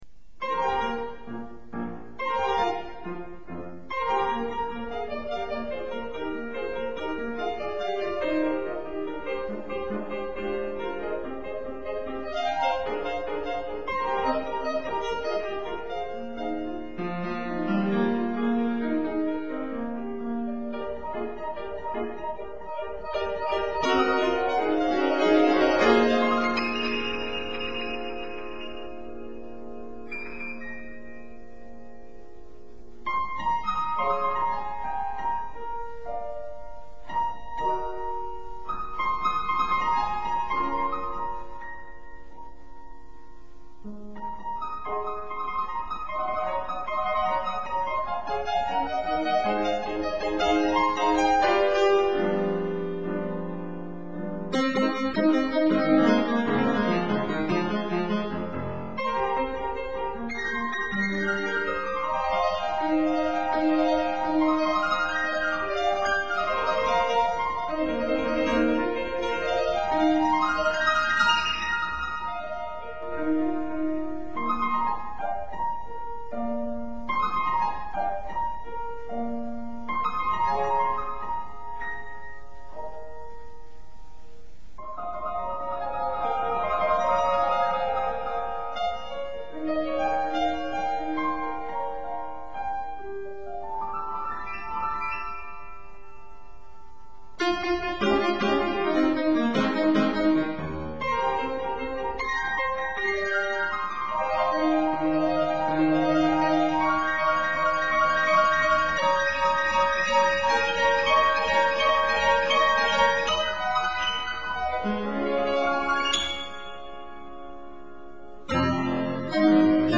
0196-钢琴名曲旱天雷.mp3